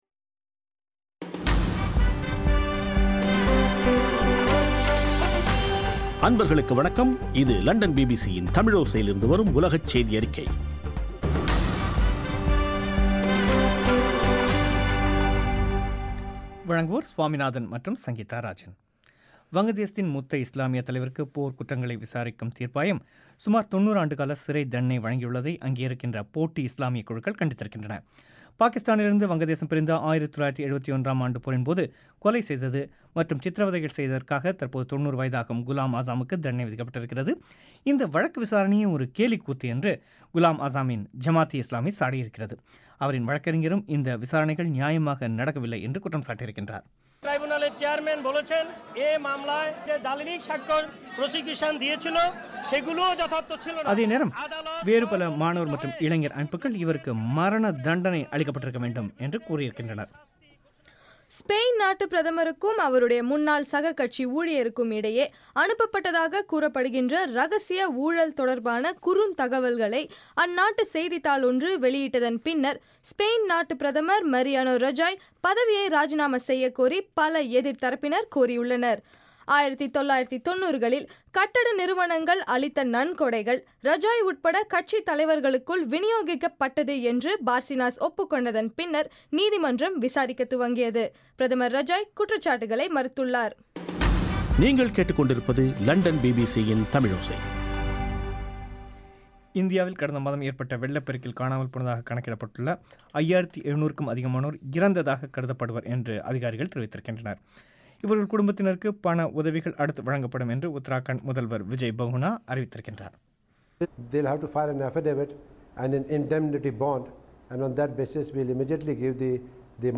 ஜூலை 15 பிபிசி தமிழோசையின் உலகச் செய்திகள்